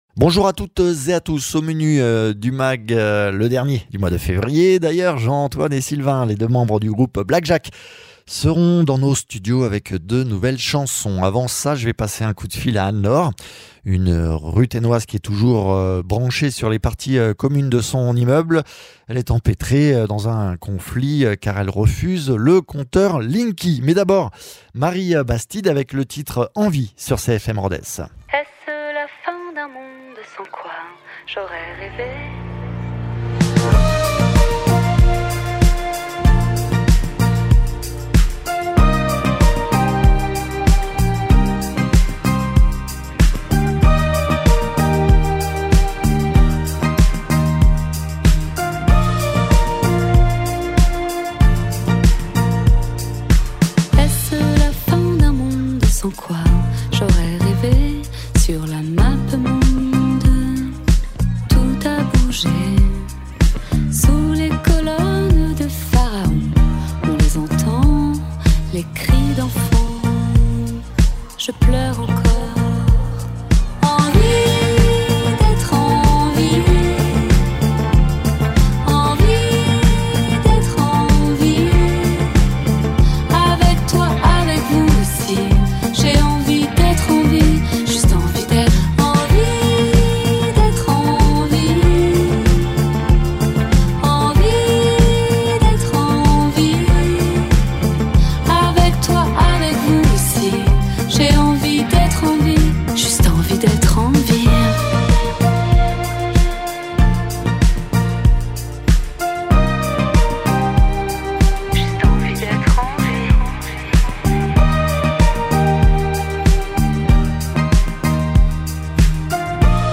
dans le mag aussi le duo Black Jacques qui vient nous interpréter deux nouvelles chansons
Mags